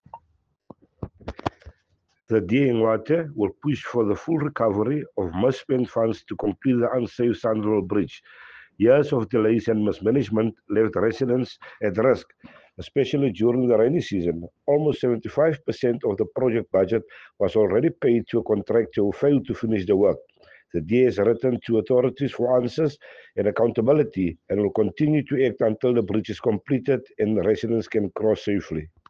Afrikaans soundbites by Cllr Robert Ferendale and Sesotho soundbite by Jafta Mokoena MPL.